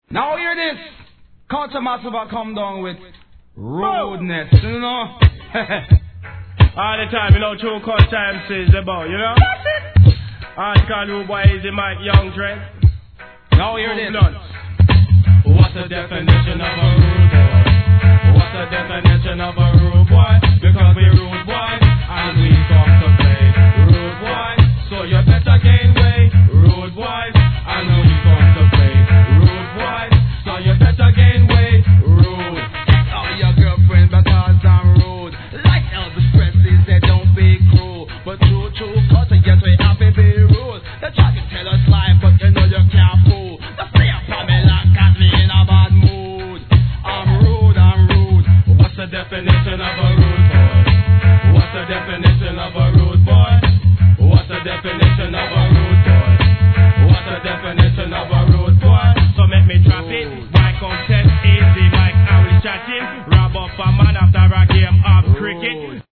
HIP HOP/R&B
HIP HOP ver.